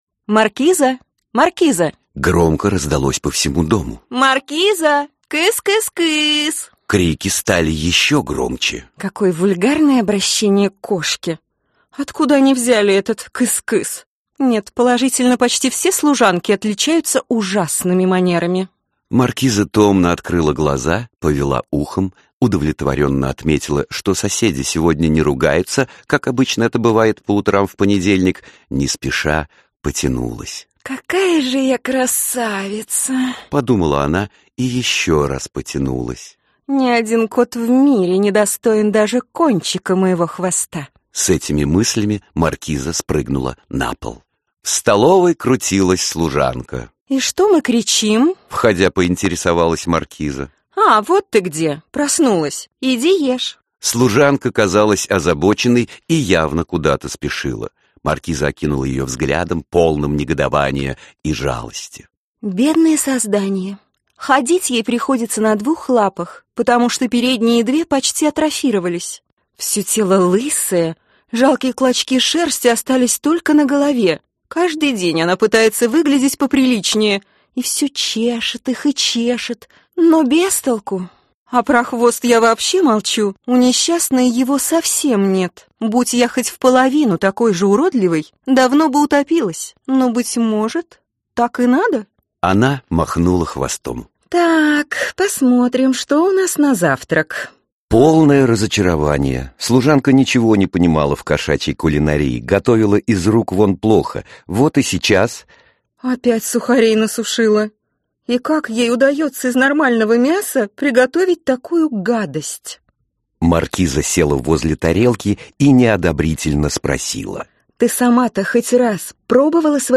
Аудиокнига 130 дней | Библиотека аудиокниг